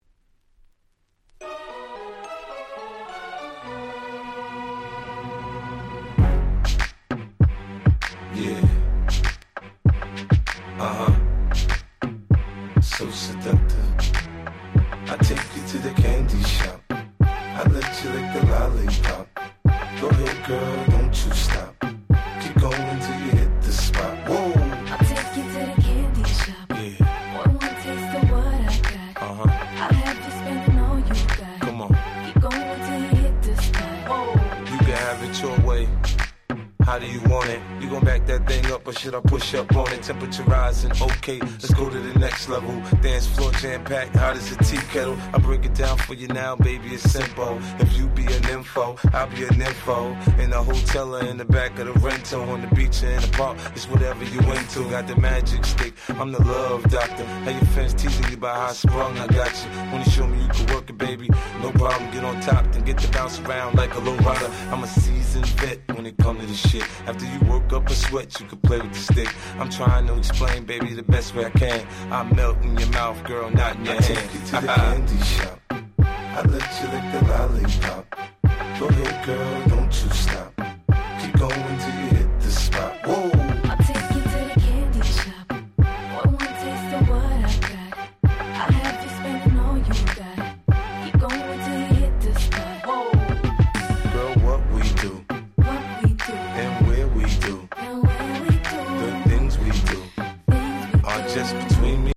05' Super Hit Hip Hop !!